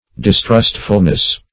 Dis*trust"ful*ly, adv. -- Dis*trust"ful*ness, n.